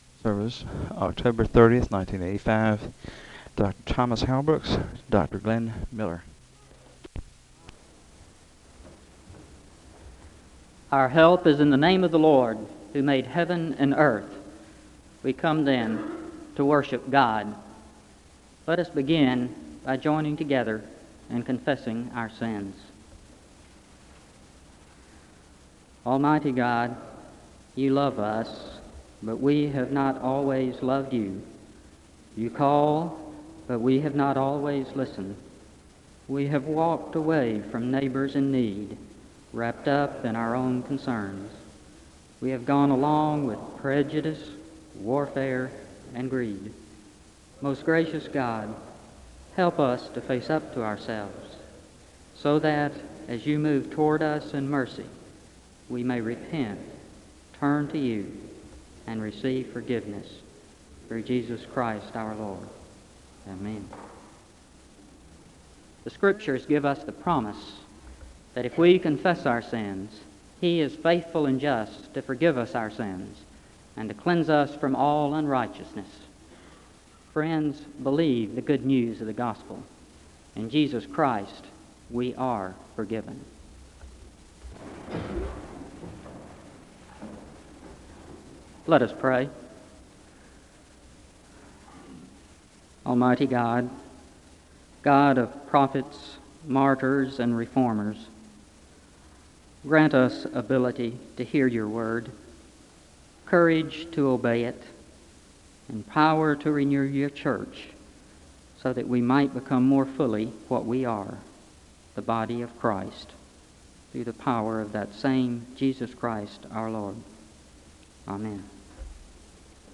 The service begins with a prayer of confession and a second general prayer (00:00-01:59).
The service ends with a benediction (22:51-23:09).